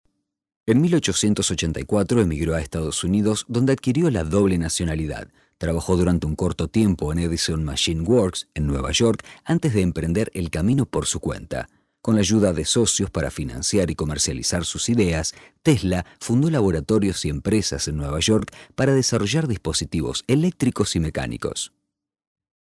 Locutor argentino.
locutor Argentina, Argentinian voice over